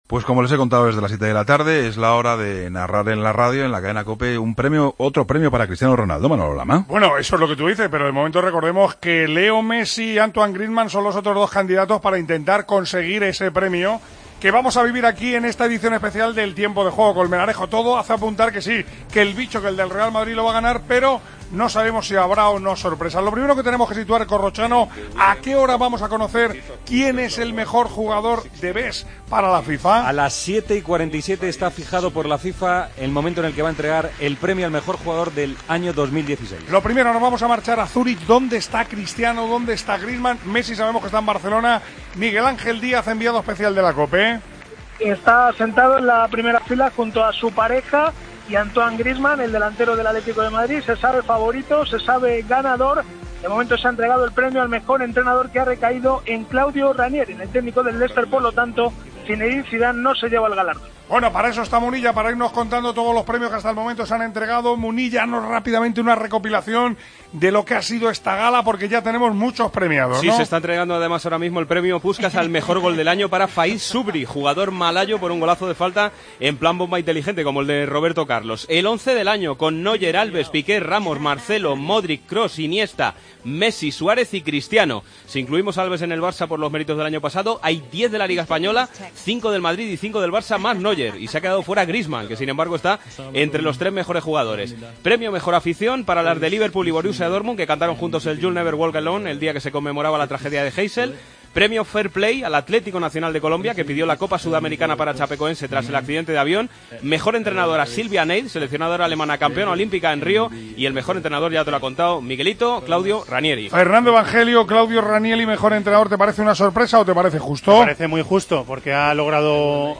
AUDIO: Escucha contamos, con Manolo Lama al frente de Deportes COPE, la gala de 'The Best' en la que Cristiano Ronaldo ganó el premio al mejor...